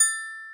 glock_A5_E5_2.ogg